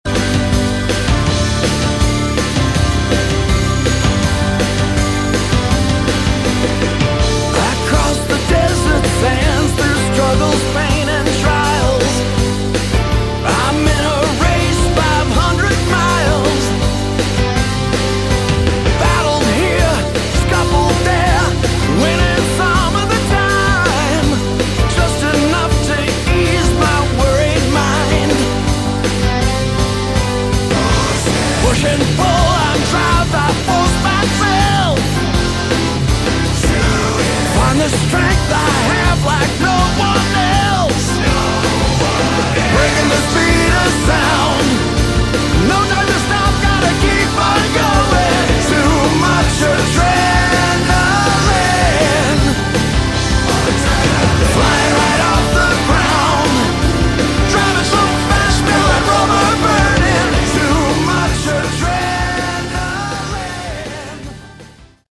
Category: Hard Rock
lead and backing vocals, lead and rhythm guitars
bass, backing vocals, keyboards, drums, programming